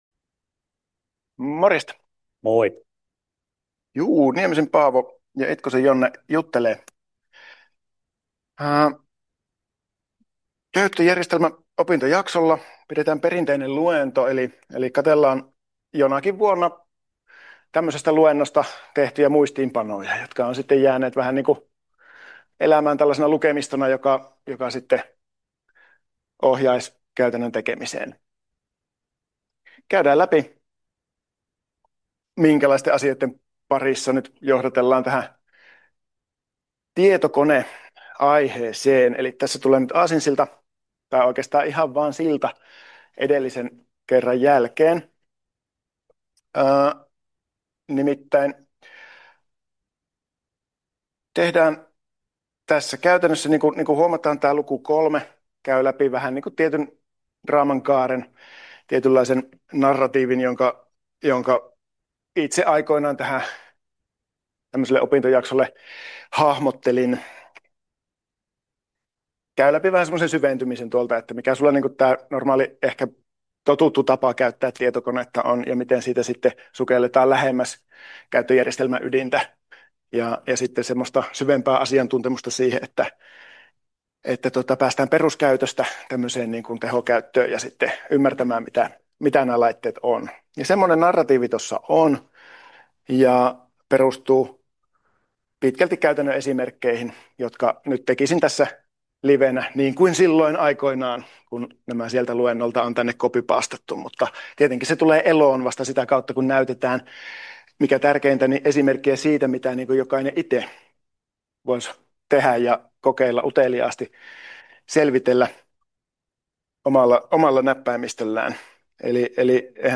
Monisteen luentaa 3 (2026 versio)